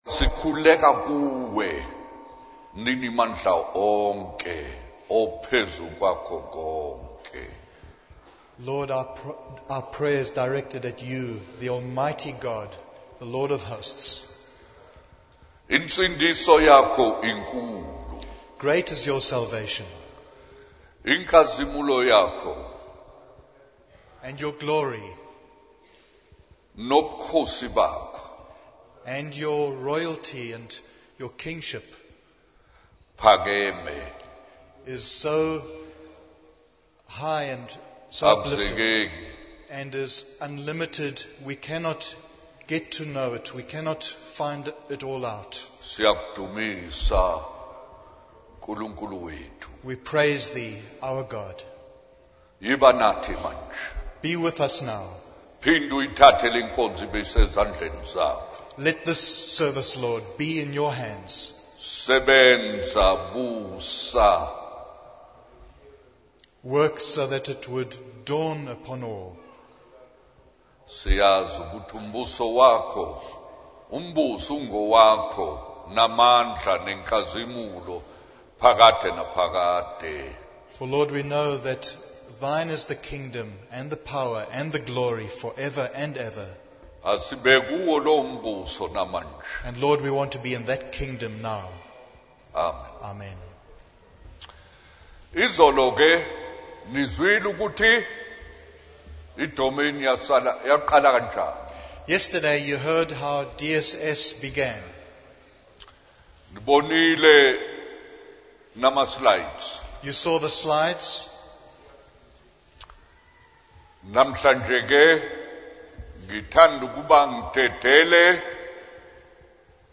In this sermon, the speaker shares their personal testimony of how they came to the school in 1996 due to a desperate situation after losing their mother at a young age.